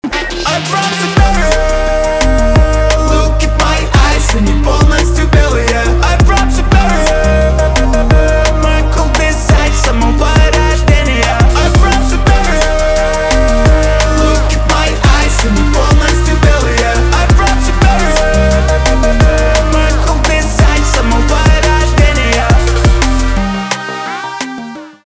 русский рэп
битовые , басы , качающие
электроника